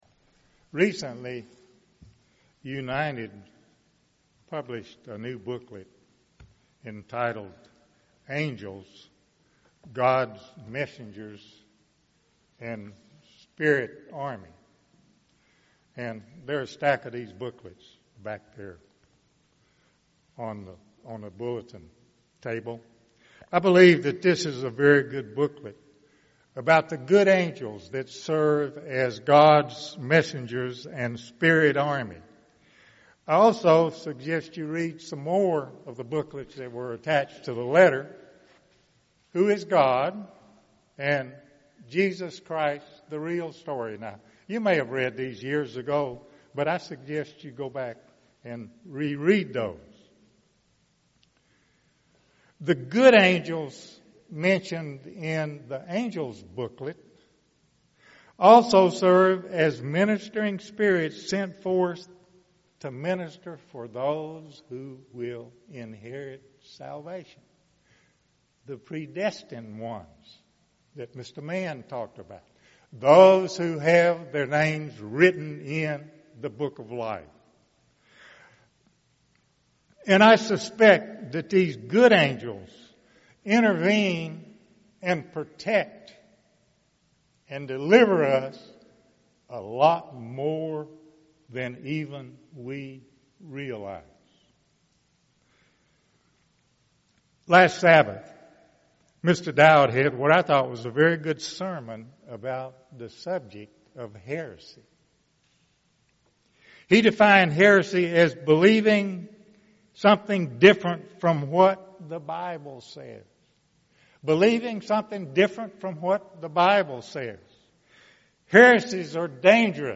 Given in Tulsa, OK